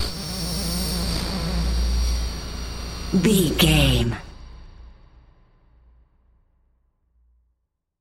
Alien Scream
Sound Effects
Atonal
scary
ominous
eerie
synthesiser
ambience
pads